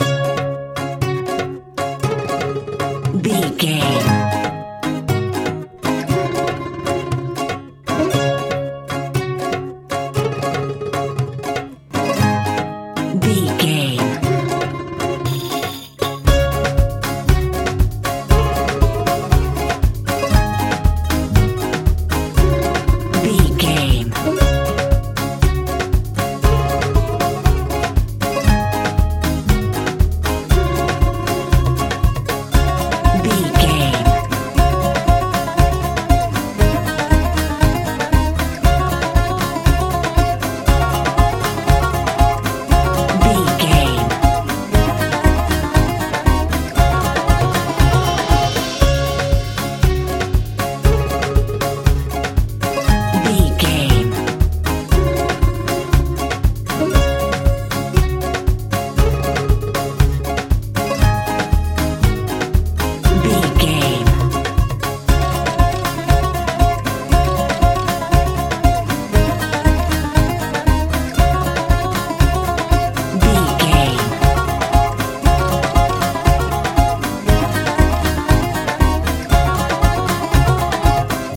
Ionian/Major
D♭
lively
energetic
bouncy